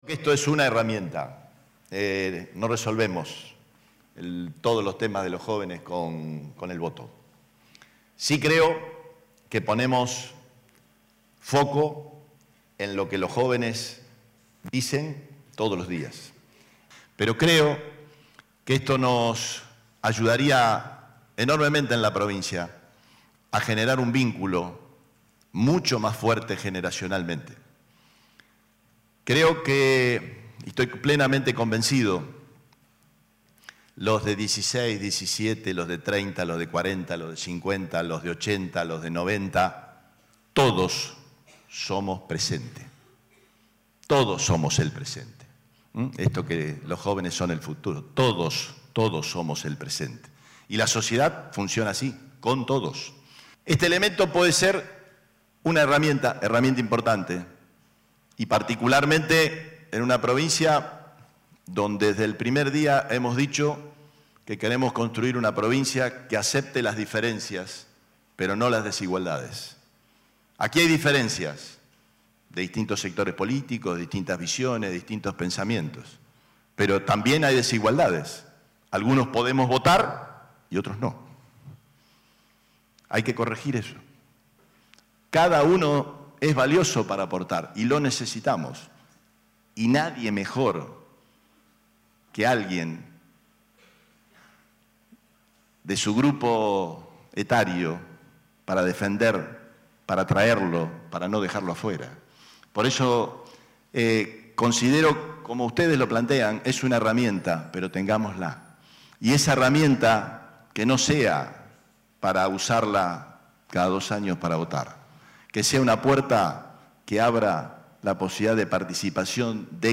El gobernador Omar Perotti participó este lunes, en la ciudad de Rosario, del primer encuentro provincial denominado “Voto Joven Santa Fe”, donde fueron analizados y debatidos proyectos sobre la ampliación de los derechos políticos de los jóvenes de entre 16 y 18 años.
Palabras del gobernador